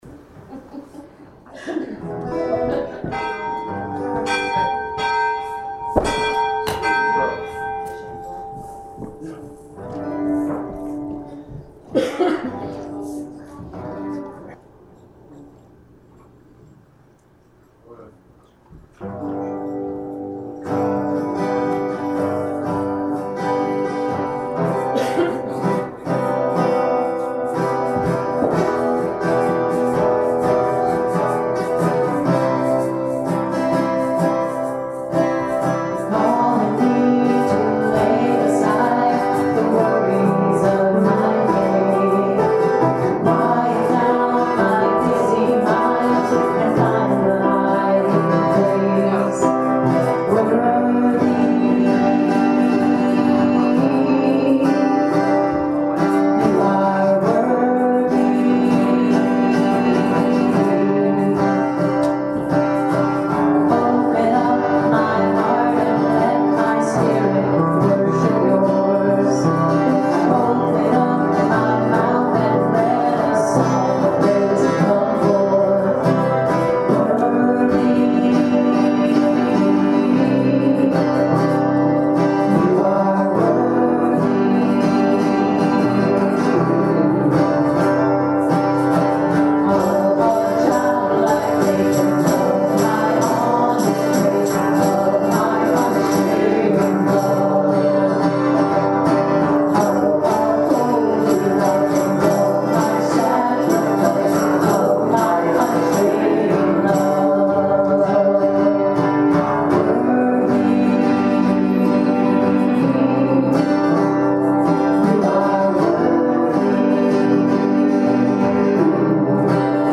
January 8th, 2017 Service Podcast
Hymn of Joy: #2 Holy Holy Holy
Benediction & Choral Amen